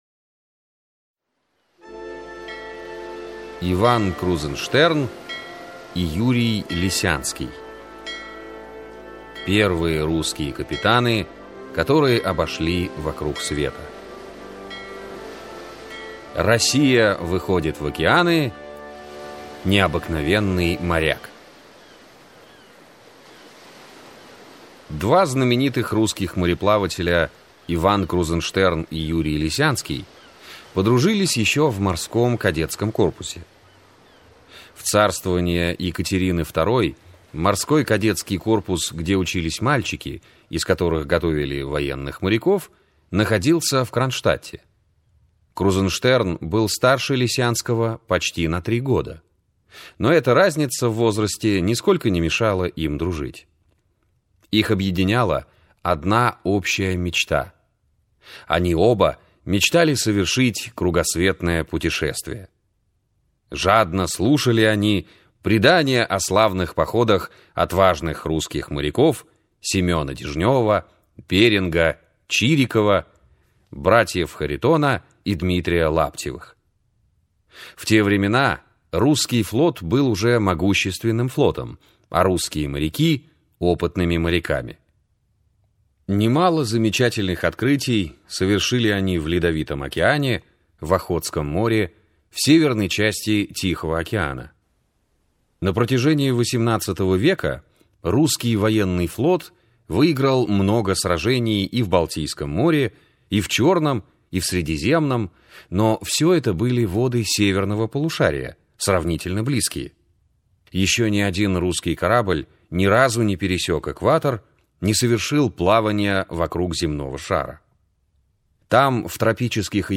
Аудиокнига Великие мореплаватели. Крузенштерн и Лисянский | Библиотека аудиокниг